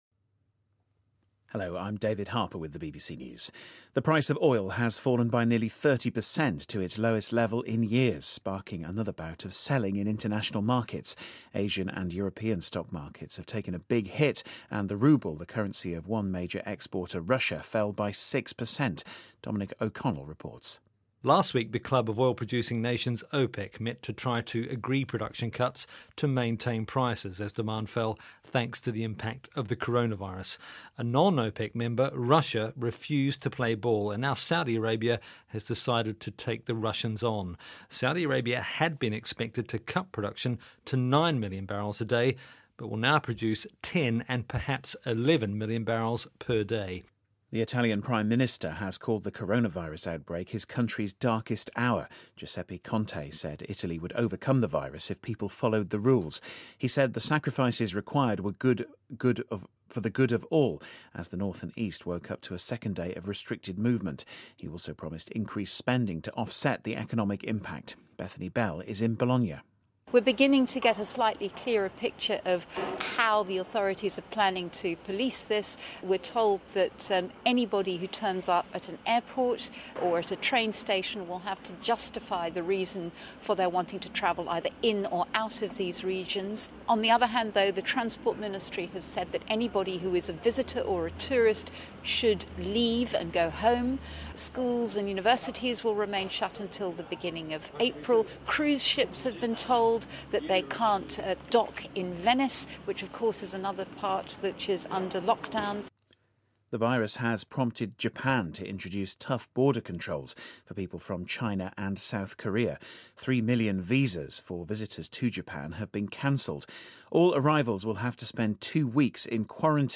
News
英音听力讲解:新冠病毒引发石油价格暴跌